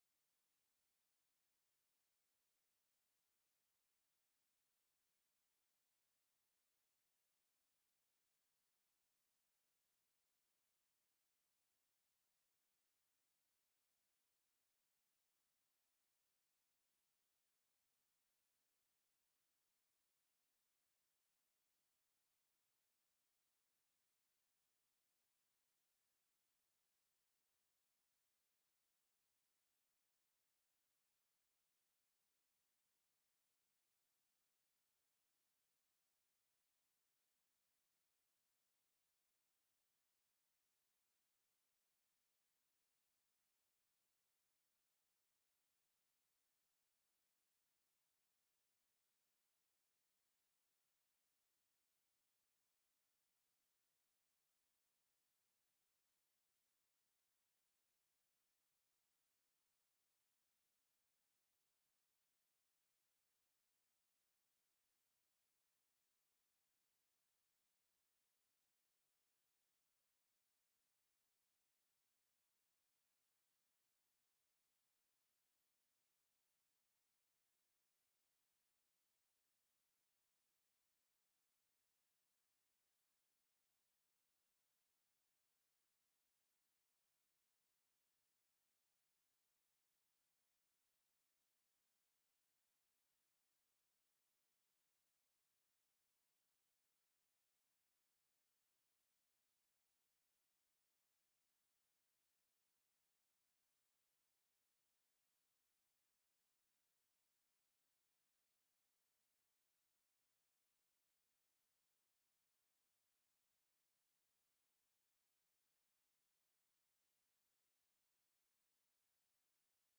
la partitura inédita para piano se escuchó en la ocasión por los acordes de instrumentos de cuerda.
Pasadas las 4:00 de la tarde, el patio del museo Casa Natal de Céspedes devino escenario de la interpretación que dista en su introducción de las adaptaciones más conocidas.